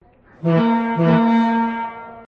horn.mp3